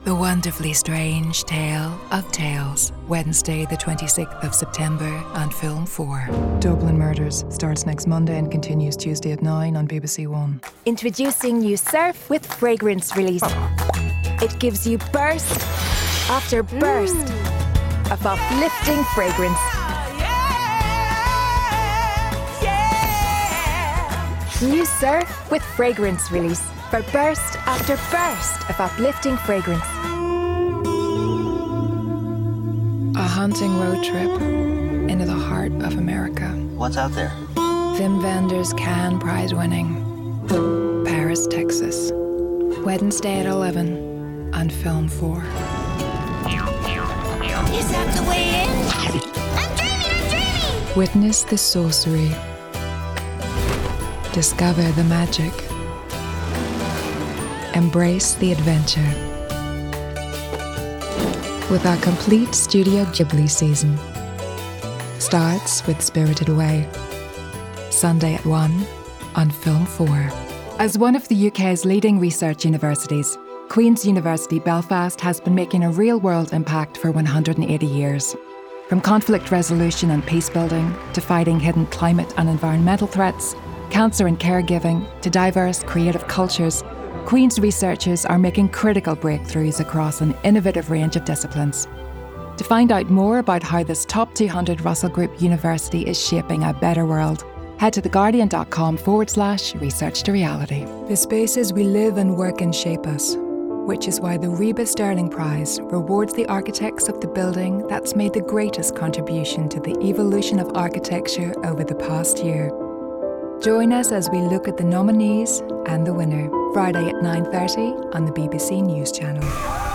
American, RP ('Received Pronunciation'), Southern Irish